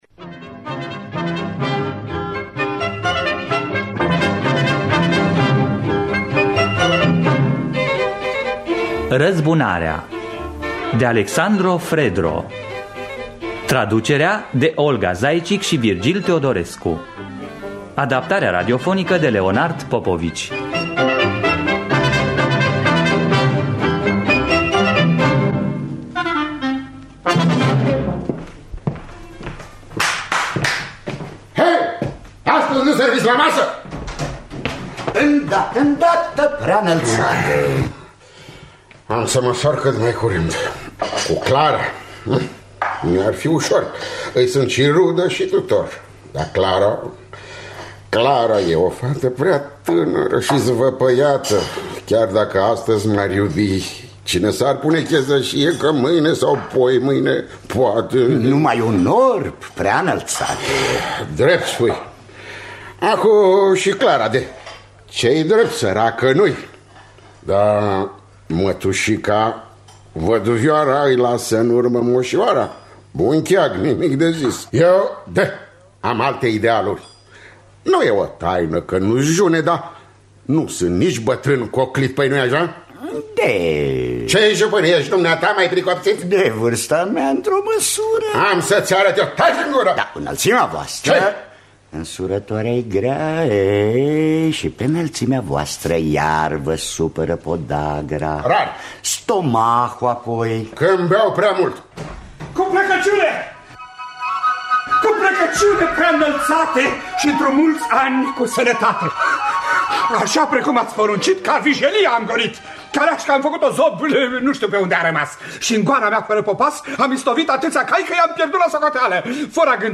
“Răzbunarea” de Aleksander (Alessandro) Fredro – Teatru Radiofonic Online